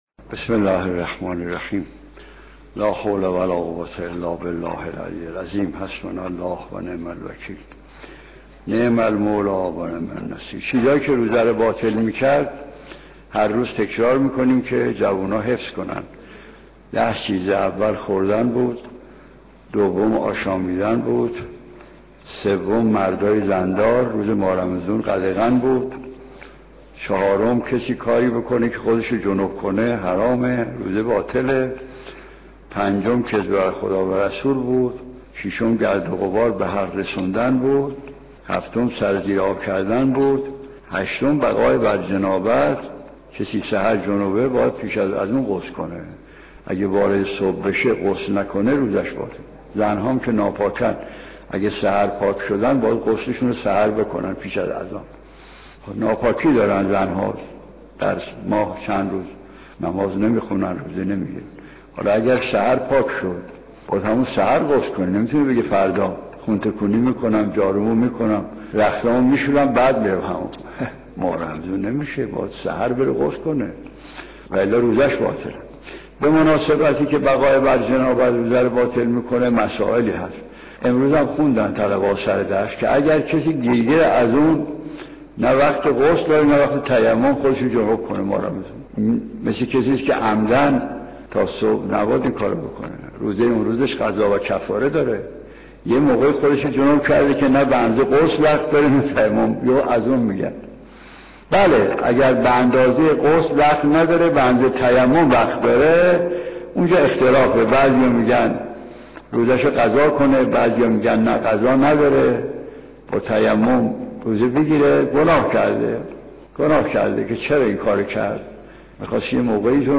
شرح کوتاه دعای روز هفدهم ماه رمضان.mp3